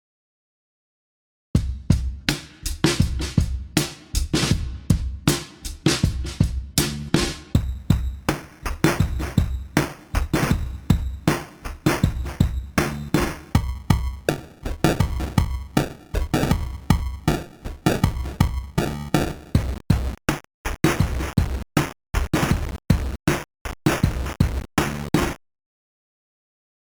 Sampleratenreduktion: Loop 1 (original), Loop 2 (8 Bit bei 4 kHz), Loop 3 (8 Bit bei 1 kHz), Loop 4 (4 Bit bei 4 kHz)
Sampleratenreduktion+Stufen.mp3